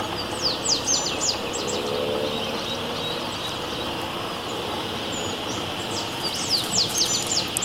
Double-collared Seedeater (Sporophila caerulescens)
Life Stage: Adult
Country: Argentina
Location or protected area: Camino a Bajo de Veliz
Condition: Wild
Certainty: Recorded vocal